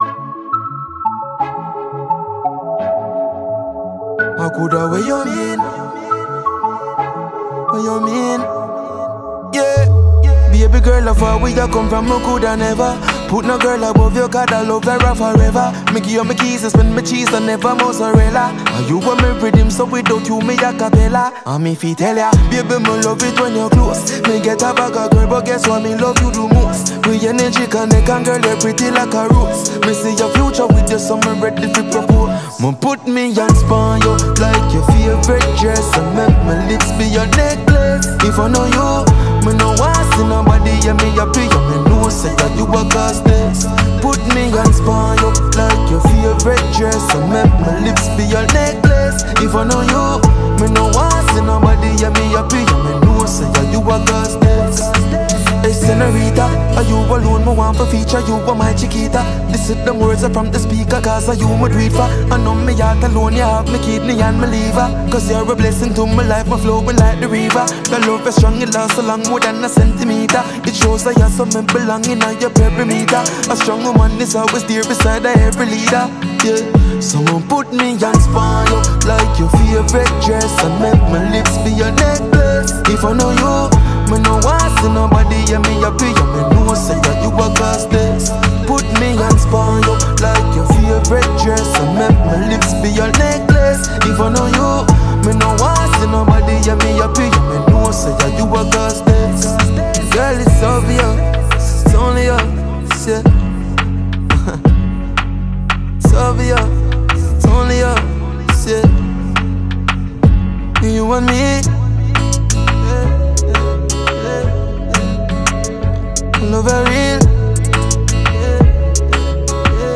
Jamaican dancehall artist